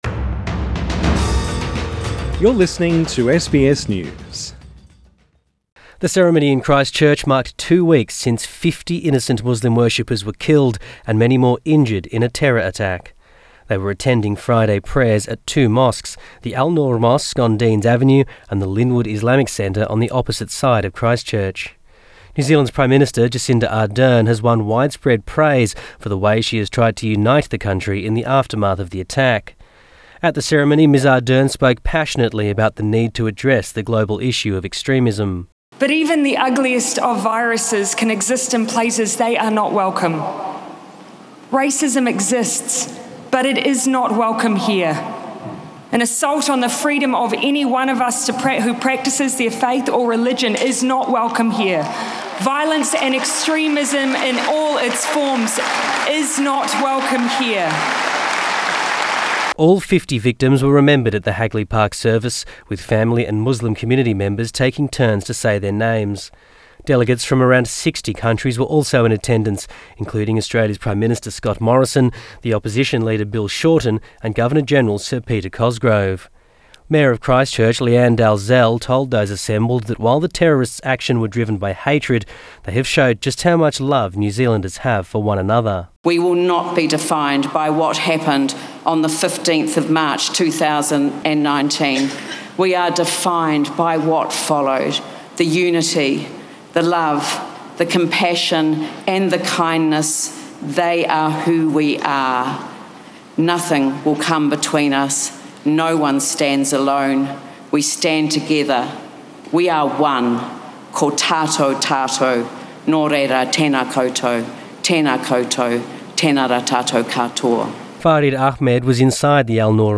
All 50 victims were remembered at the Hagley Park service, with family and Muslim community members taking turns to say their names.